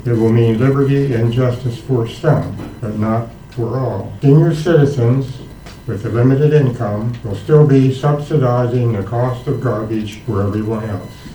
The passage of a 73% trash rate increase did not go without comment at this week’s Cumberland Mayor and Council meeting. Before passing the new rate, former councilman Harvey May requested amending the increase, saying it negates the pledge they make at every meeting of “liberty and justice for all.”